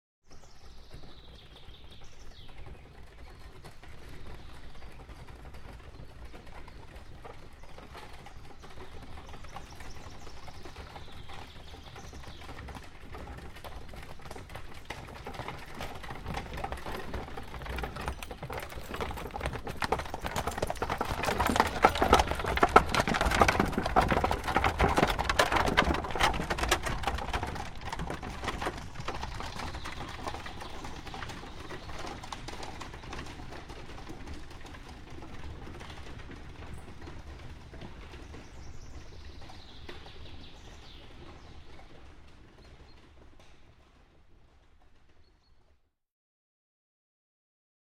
Здесь собраны натуральные записи: от мерного цокота копыт до характерного скрипа деревянных осей.
Шум повозки, катящейся сквозь лес в знойный летний день